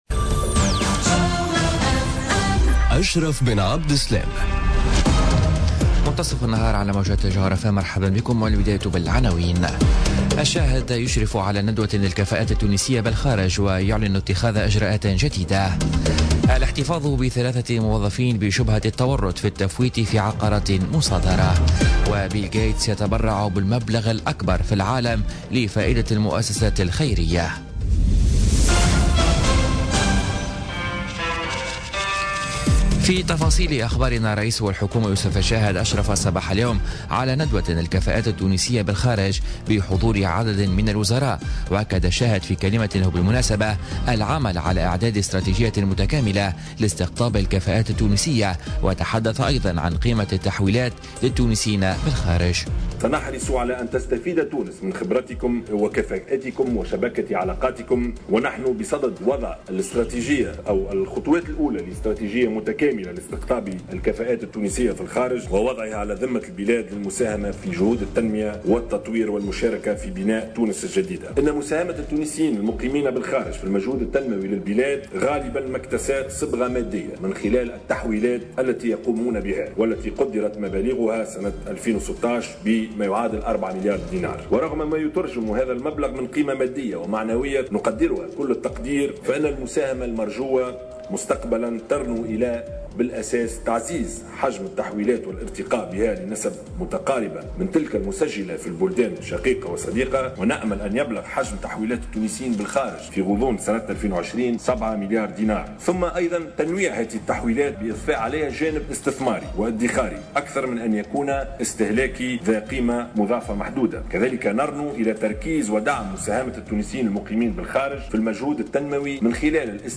نشرة أخبار منتصف النهار ليوم الإربعاء 16 أوت 2017